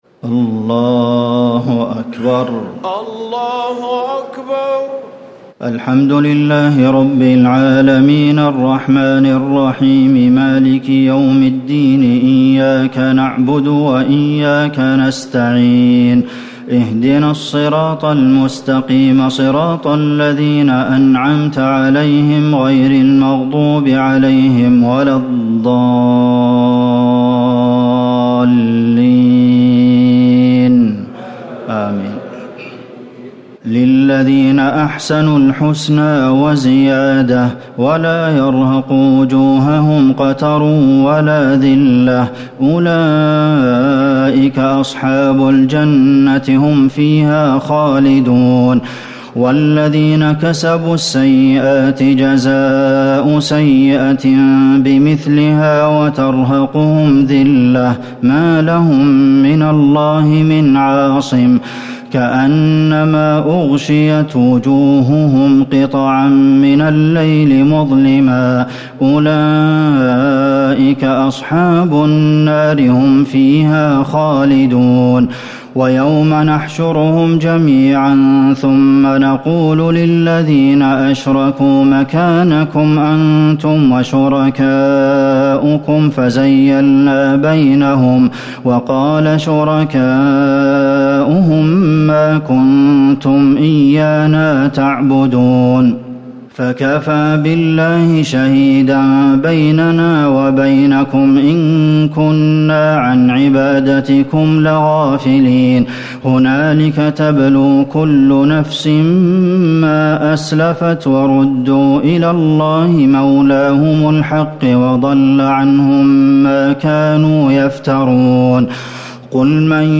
تراويح الليلة العاشرة رمضان 1439هـ من سورتي يونس (26-109) و هود (1-5) Taraweeh 10 st night Ramadan 1439H from Surah Yunus and Hud > تراويح الحرم النبوي عام 1439 🕌 > التراويح - تلاوات الحرمين